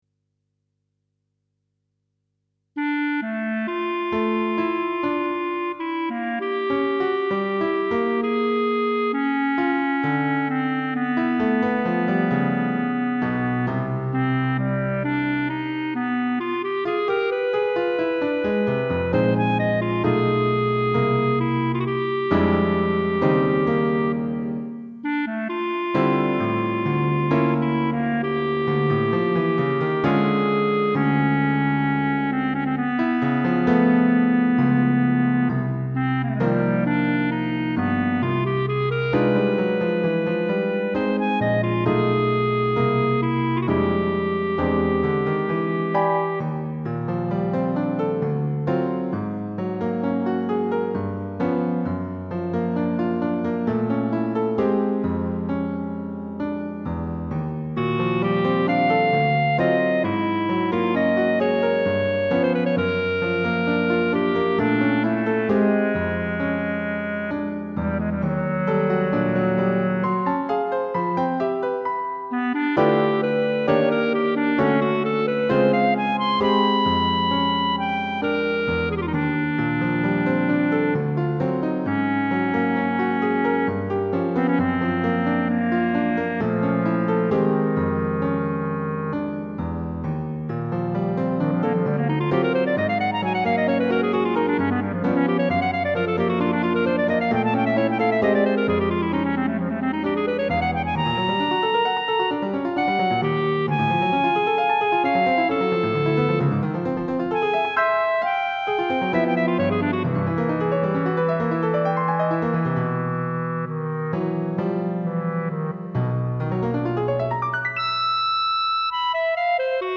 Midi version for demo only